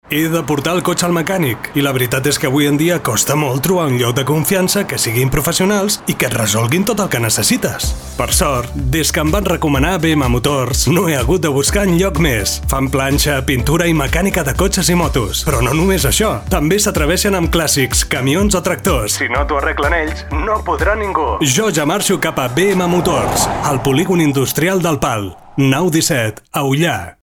Anunci-BM-Motors.mp3